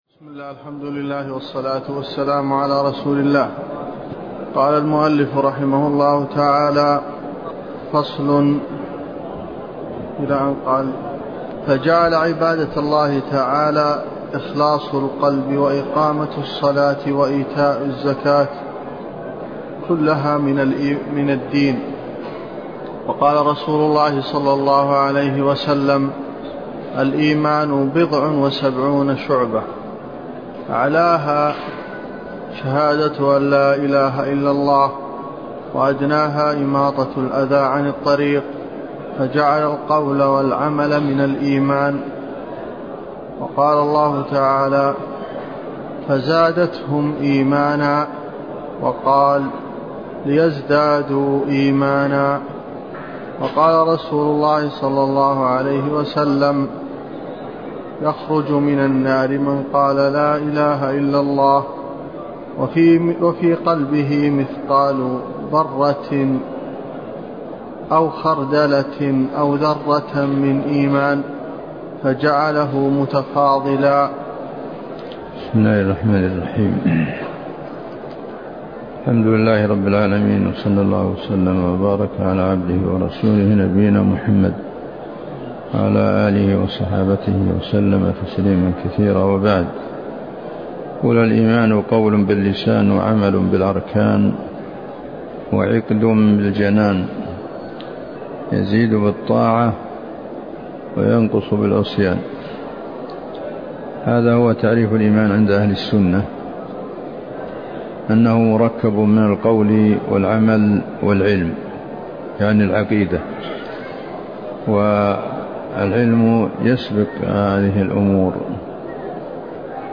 الدرس (8) شرح لمعة الاعتقاد - الشيخ عبد الله بن محمد الغنيمان